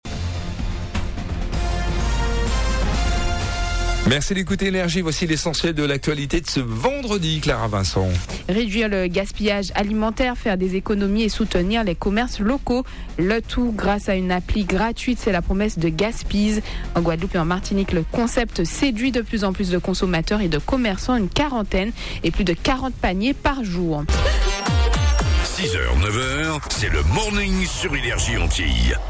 Ce matin, c’est sur NRJ Antilles que notre appli a fait parler d’elle 🌴🎧 Un réveil en fanfare pour toute l’équipe… et apparemment pour vous aussi, vu le nombre de connexions juste après 😍.